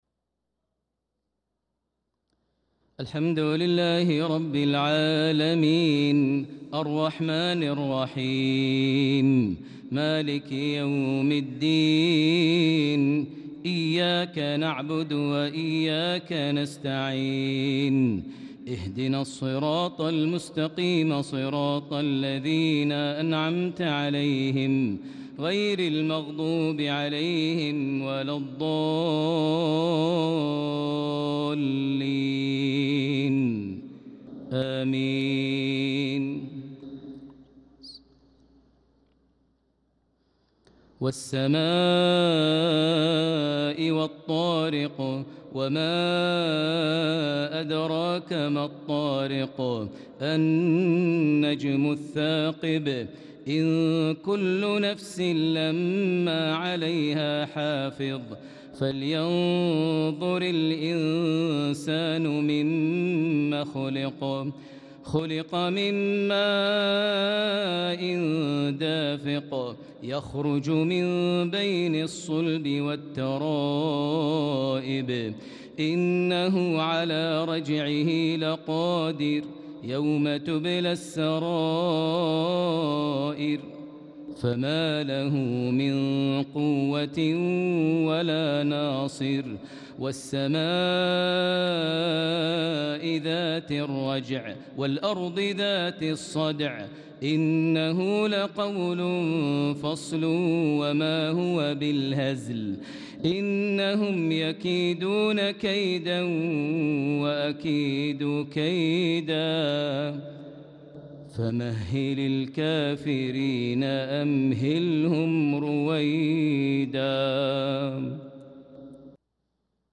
صلاة العشاء للقارئ ماهر المعيقلي 3 ذو الحجة 1445 هـ